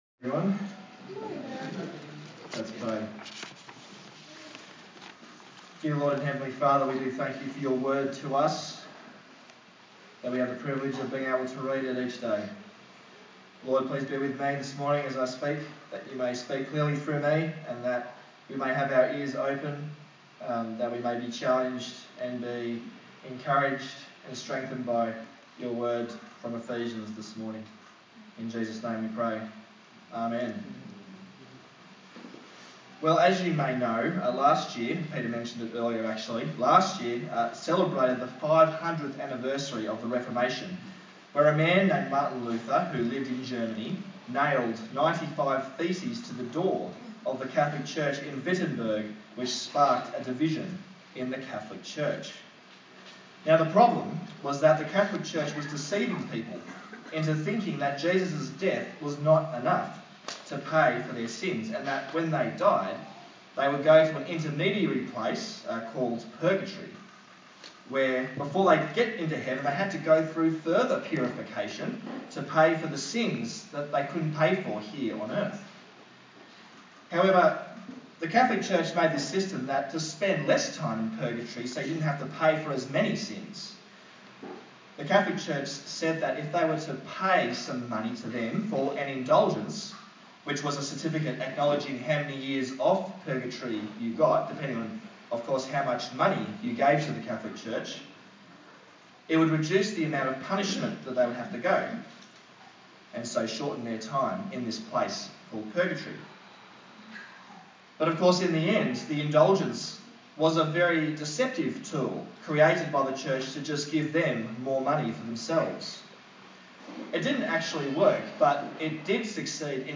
A sermon on the book of Ephesians
Service Type: Sunday Morning